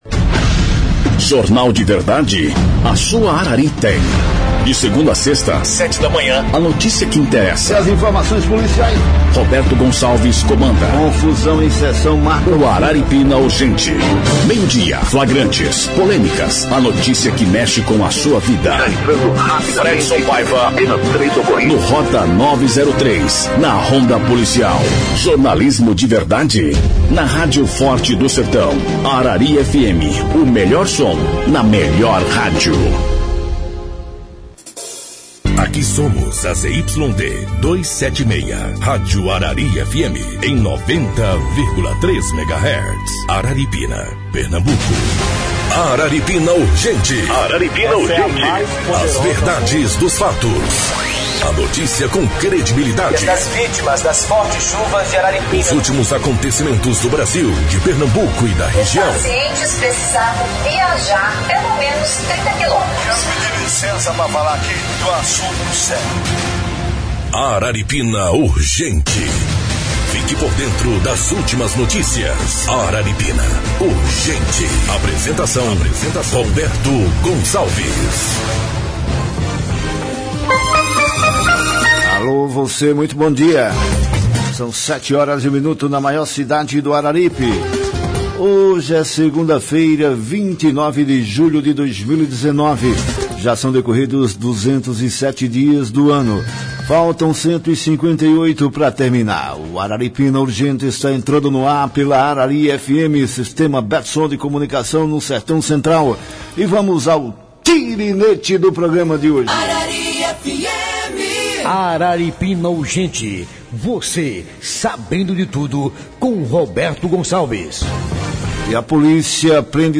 pela Arari FM 90,3